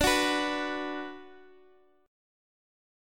Listen to B5/D strummed